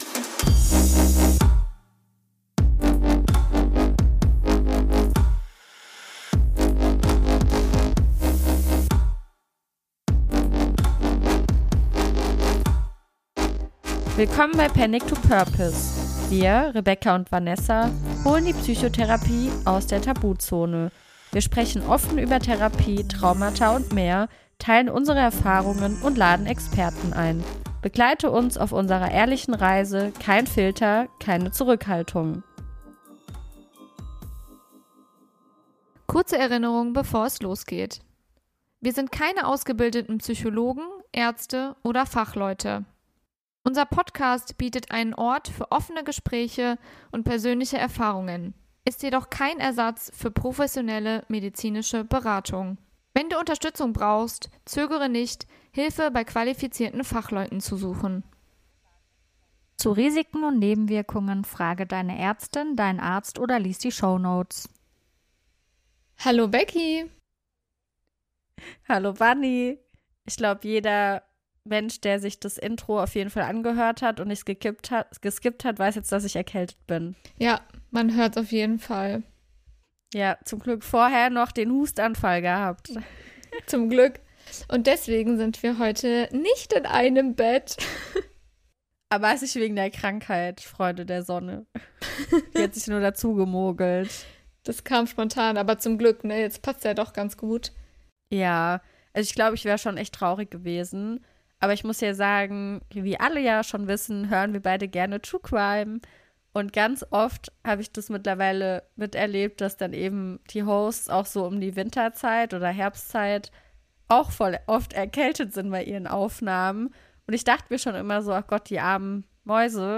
Unsere erste remote Folge – und gleich was ganz Besonderes.